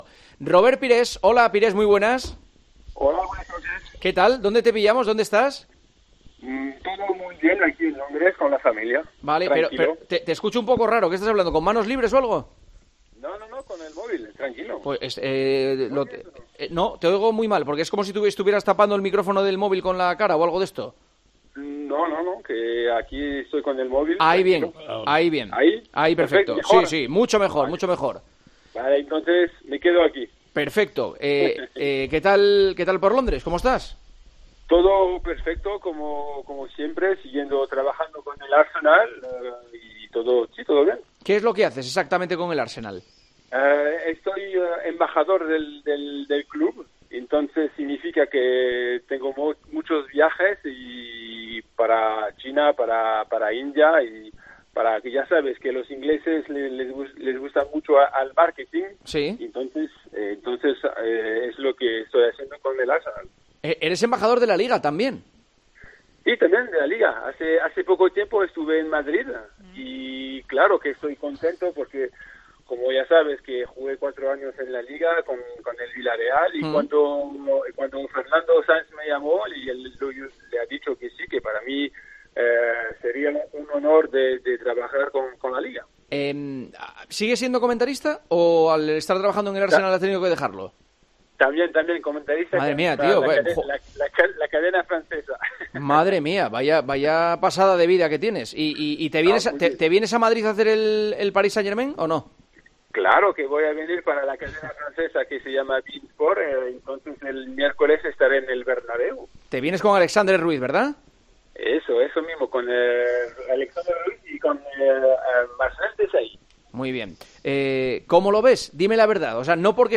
Entrevista en El Partidazo de COPE